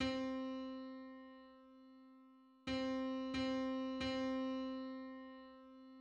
Being diminished, it is considered a dissonant interval.[4]
Diminished second
Unison_on_C.mid.mp3